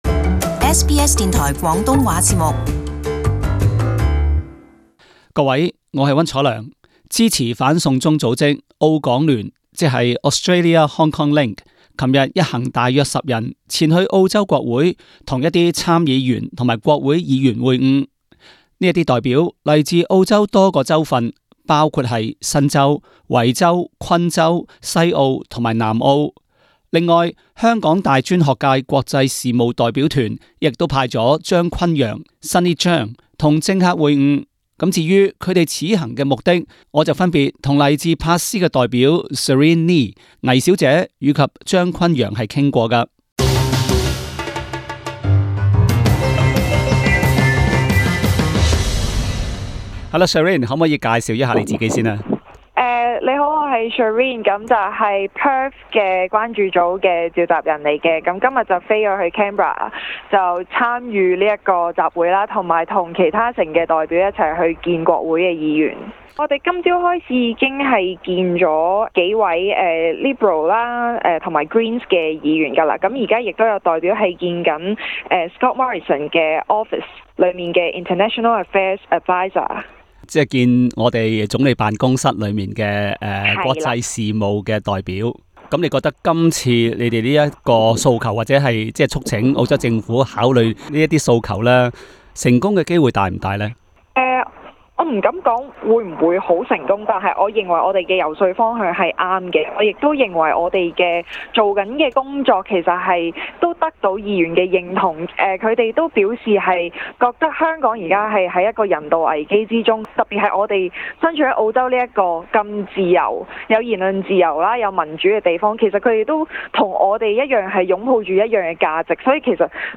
請聼他以下這個報導。